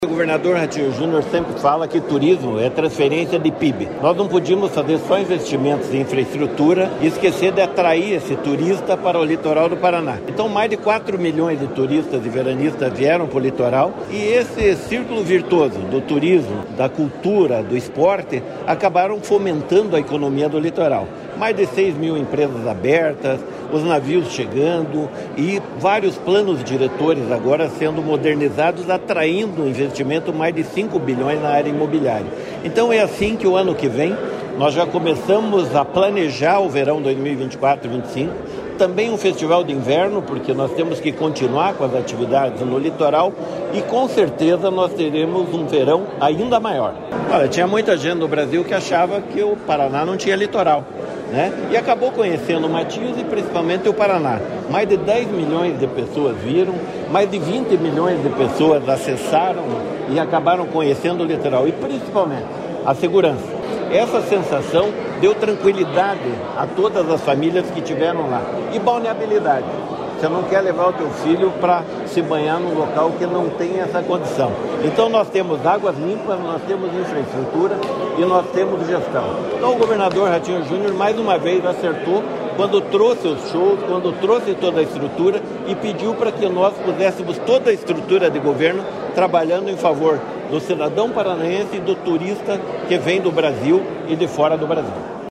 Sonora do secretário do Esporte, Helio Wirbiski, sobre o balanço do Verão Maior Paraná 2023/2024